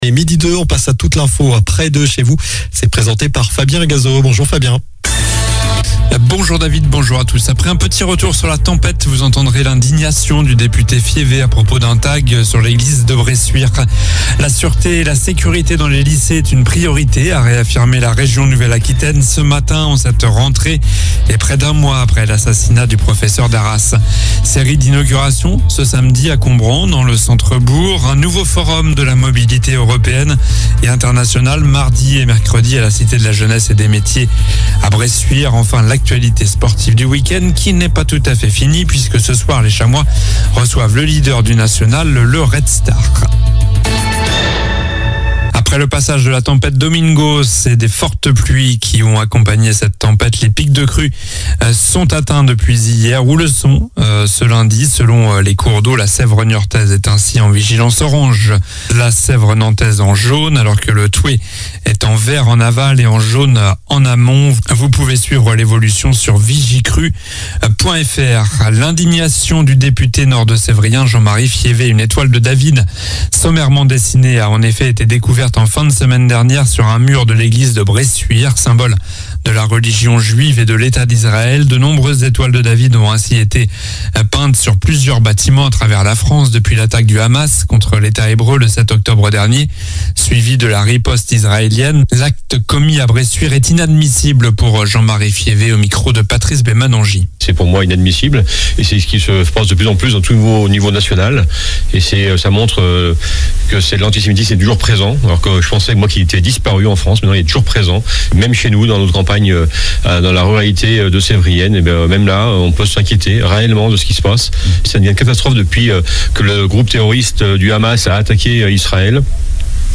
Journal du lundi 06 novembre (midi)